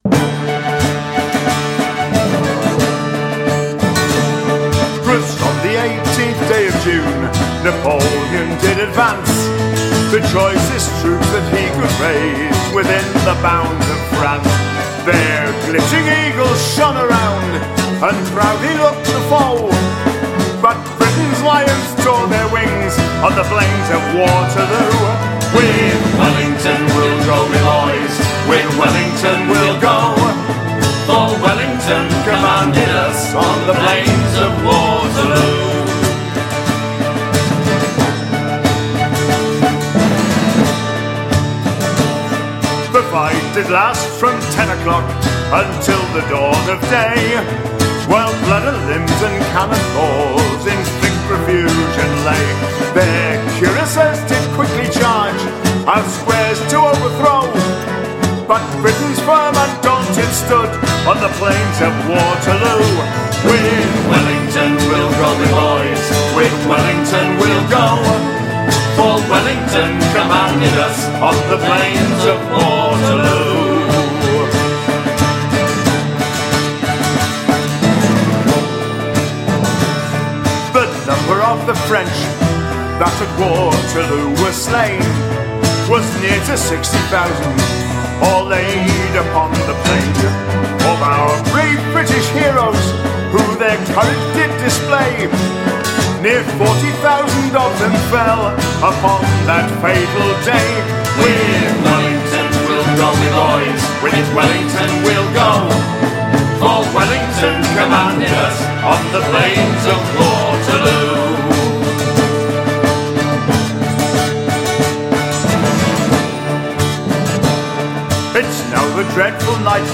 Popular Music of the Olden
English traditional music